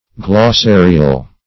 Search Result for " glossarial" : The Collaborative International Dictionary of English v.0.48: Glossarial \Glos*sa"ri*al\, a. Of or pertaining to glosses or to a glossary; containing a glossary.